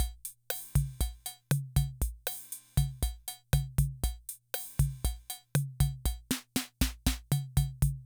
Index of /90_sSampleCDs/300 Drum Machines/Korg Rhythm 55/Korg Rhythm 55 Sample Pack_Audio Files
Korg Rhythm 55 Sample Pack_Loop21.wav